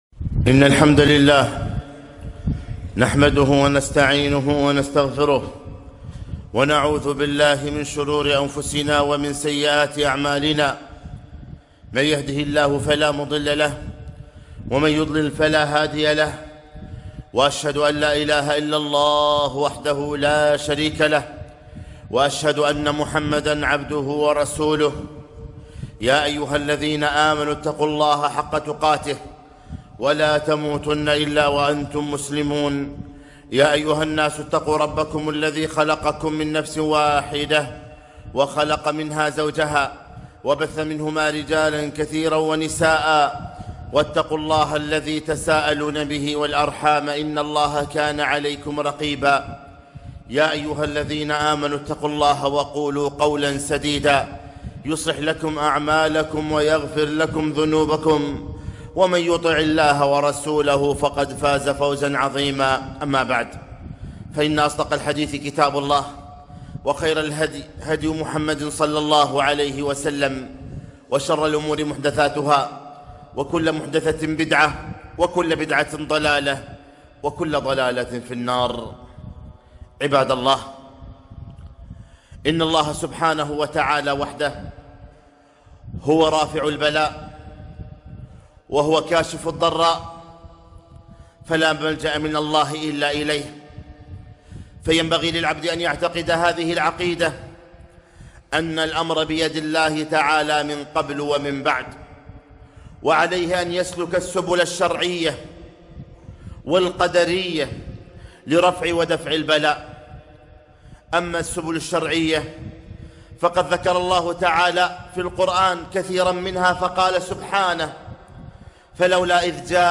خطبة - المنجيات من البلايا والمحن